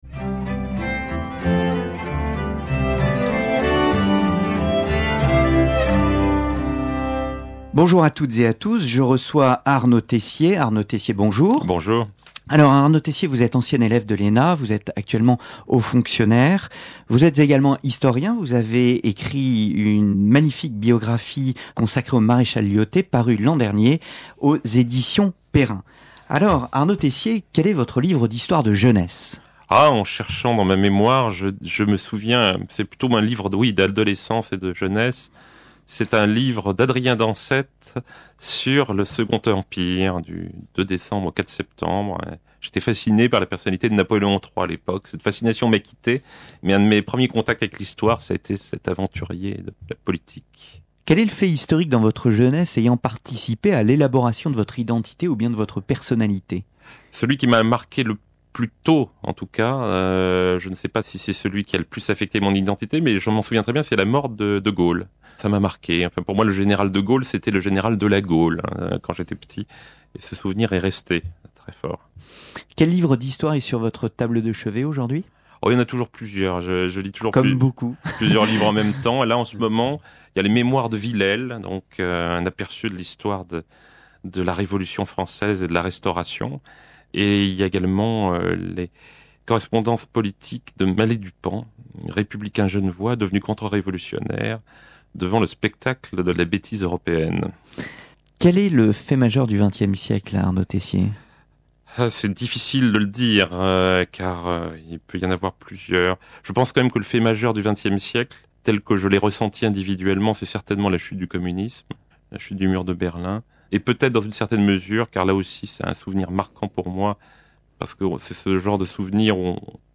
L’invité de l’émission Un Jour dans l’Histoire se livre à un bref questionnaire portant sur ses goûts et ses expériences personnelles.